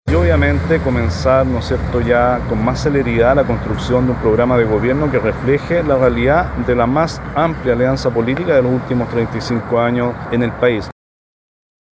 El diputado Eric Aedo (DC), que pronto se sumará al comando de la candidata, planteó que “donde hubo un error, hay que corregir y seguir adelante, cosa que se está haciendo”.